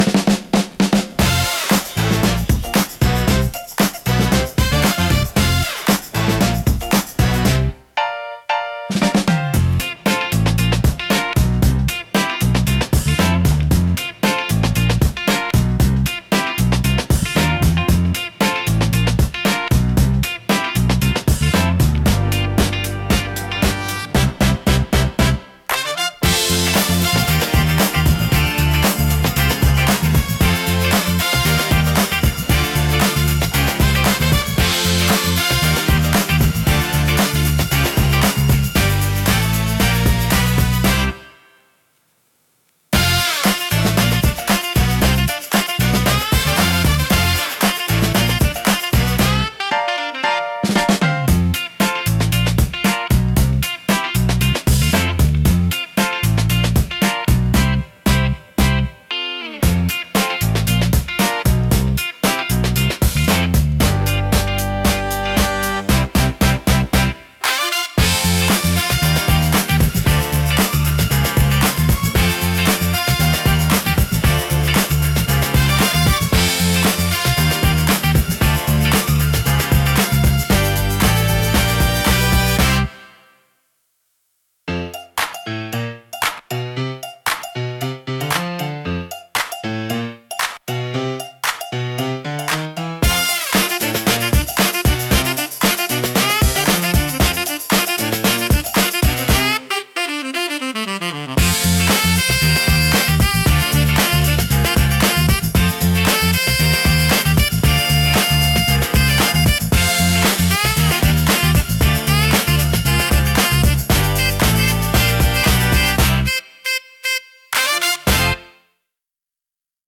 親しみやすいサウンドとポップな雰囲気、明るく感情的な楽曲が多いです。
心に残るハーモニーと温かさが魅力のジャンルです。